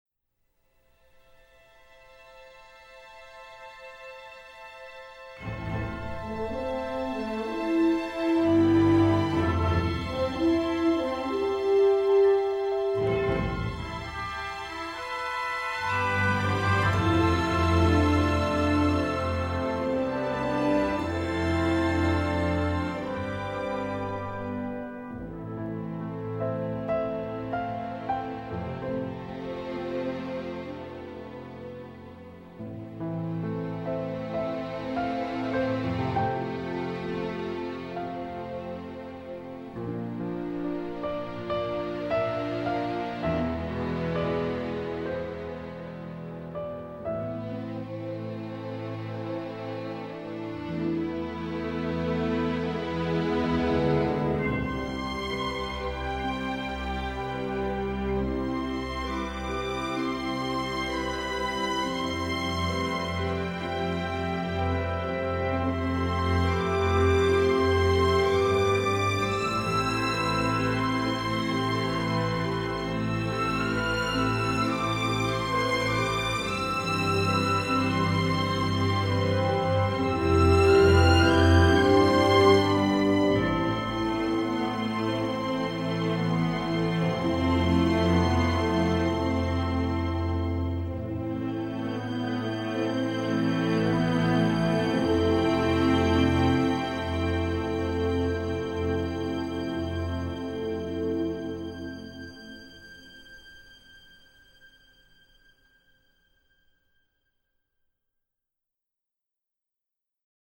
orchestral instrumental